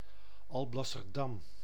Alblasserdam (Dutch: [ɑlˌblɑsərˈdɑm]
Nl-Alblasserdam.ogg.mp3